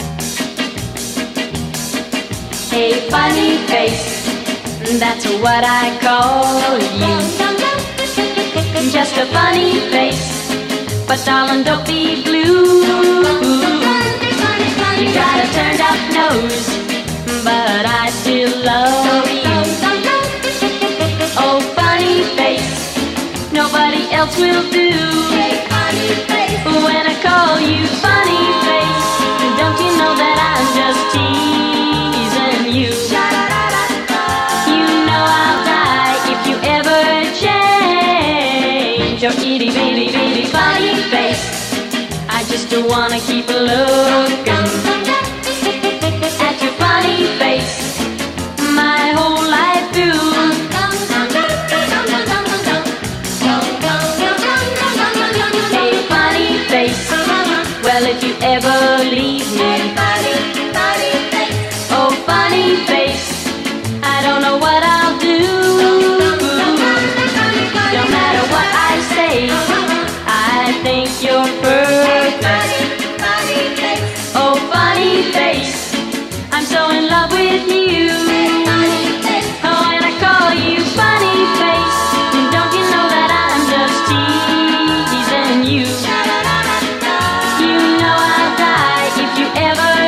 EASY LISTENING / VOCAL / JAZZ VOCAL / SWING